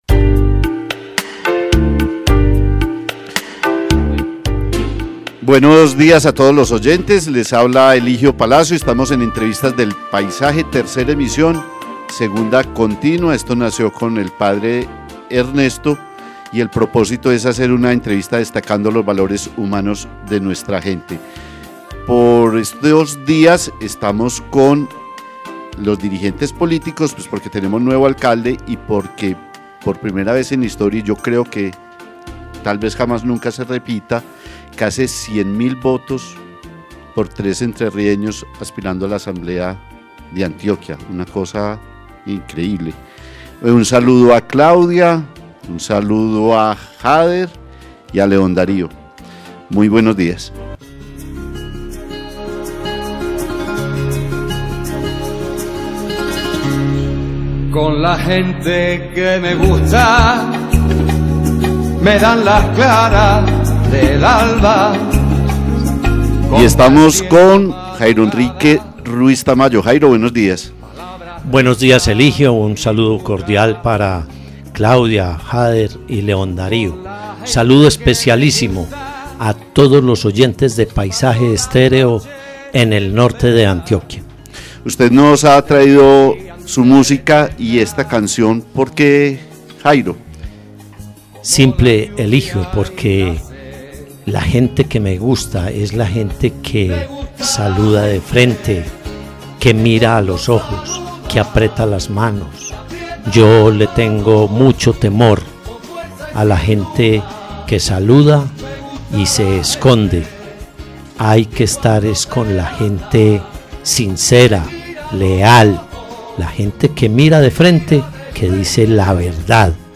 JAIRO ENRIQUE RUIZ TAMAYO - DIPUTADO ANTIOQUEÑO - ENTREVISTA Entrevista en Paisaje Estéreo, con Jairo Enrique Ruiz Tamayo, alcalde de Entrerríos en tres oportunidades: 1990-1992, 1998-2000 y 2008-2011, y actual Diputado a la Asamblea De Antioquia.